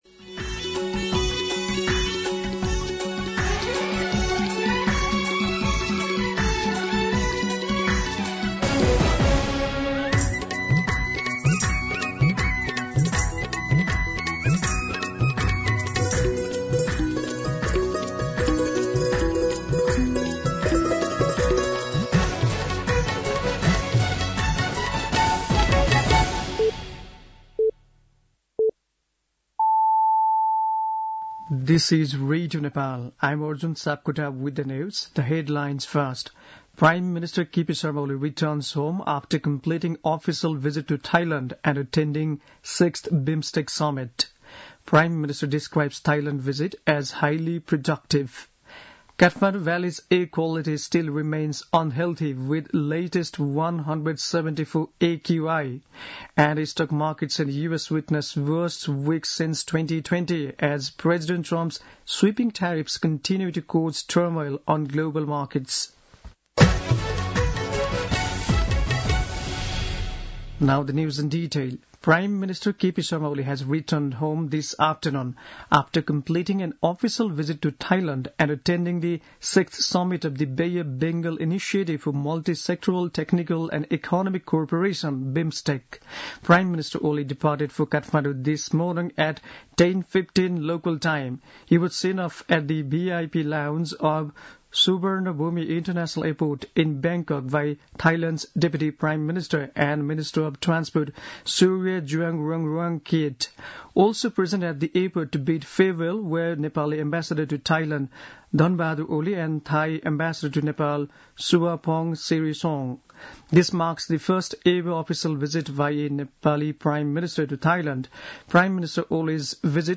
दिउँसो २ बजेको अङ्ग्रेजी समाचार : २३ चैत , २०८१
2pm-English-News.mp3